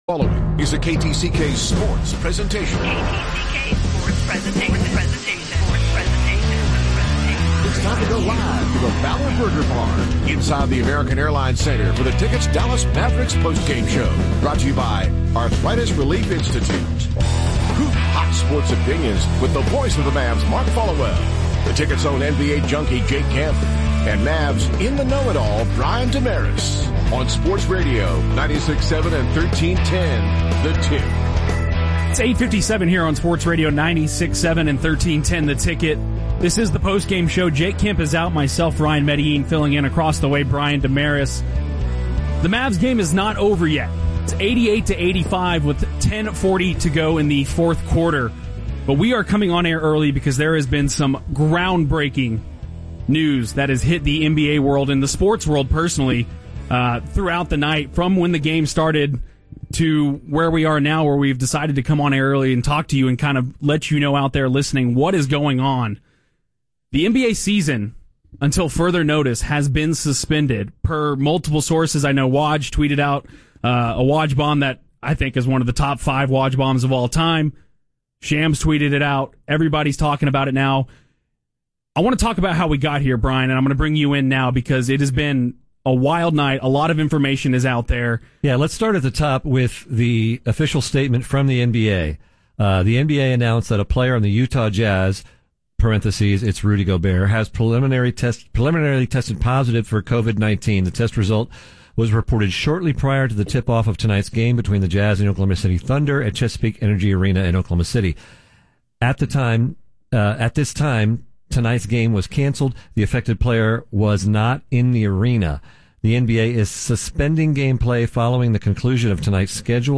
The Covid Emergency Broadcast - 3.11.20 - The UnTicket